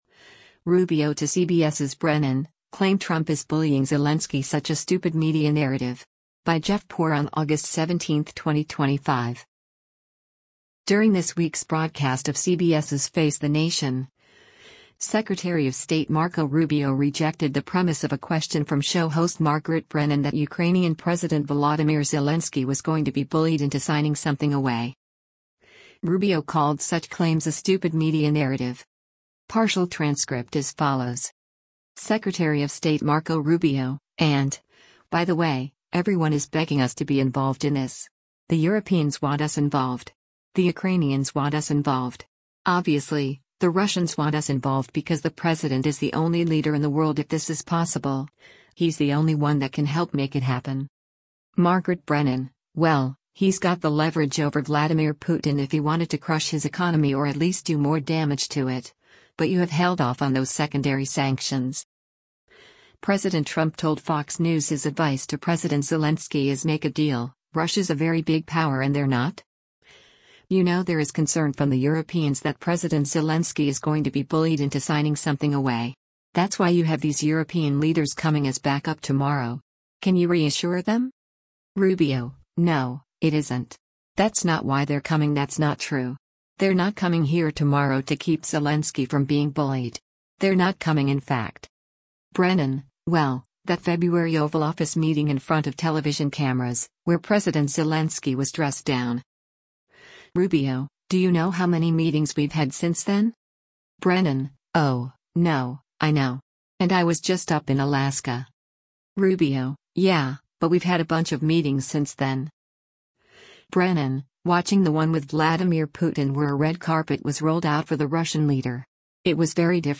During this week’s broadcast of CBS’s “Face the Nation,” Secretary of State Marco Rubio rejected the premise of a question from show host Margaret Brennan that Ukrainian President Volodymyr Zelensky was going to be “bullied into signing something away.”